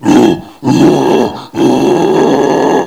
assets/nx/nzportable/nzp/sounds/zombie/r8.wav at 1ef7afbc15f2e025cfd30aafe1b7b647c5e3bb53